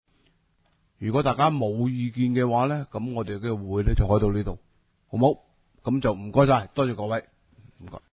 委员会会议的录音记录
地点: 将军澳坑口培成路38号 西贡将军澳政府综合大楼三楼 西贡区议会会议室